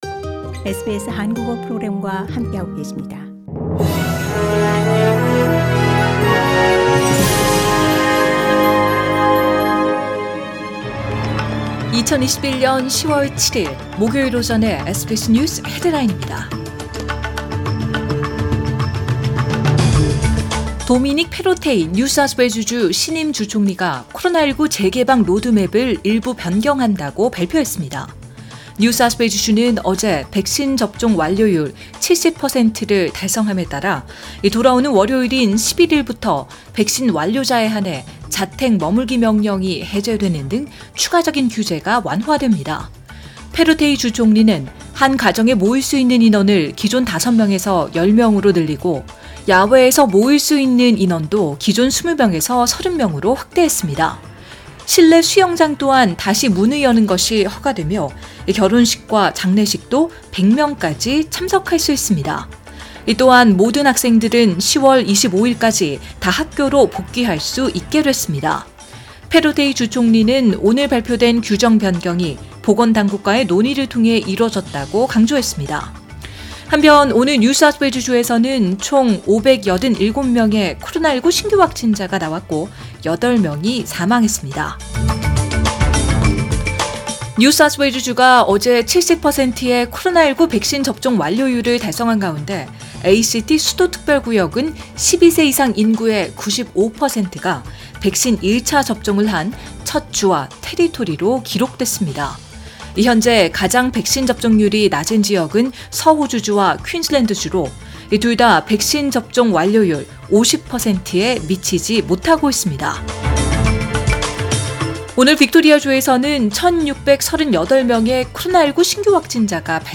2022년 10월 7일 금요일 아침 SBS 한국어 간추린 주요 뉴스입니다.